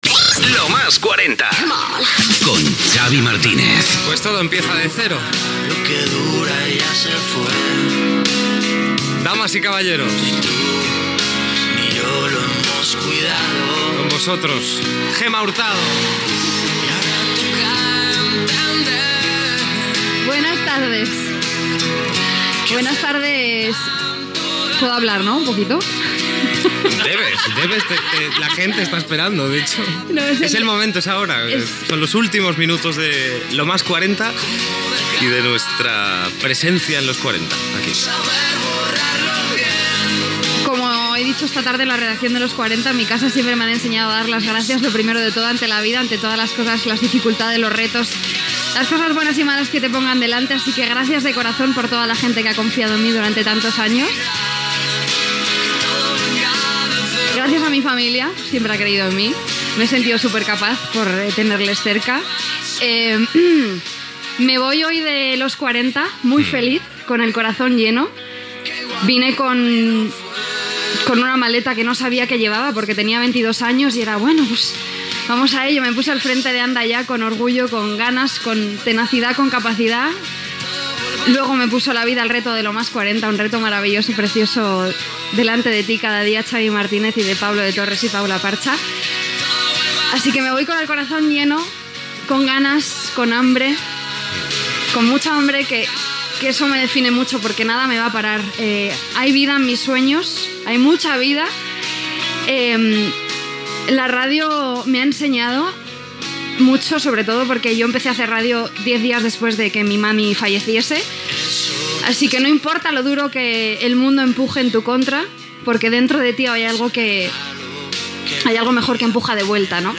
Gènere radiofònic Musical
Banda FM